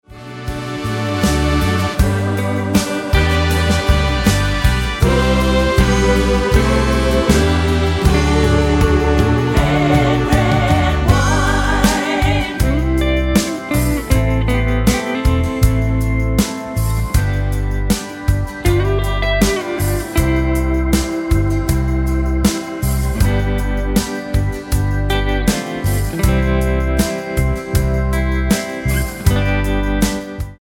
Tonart:G mit Chor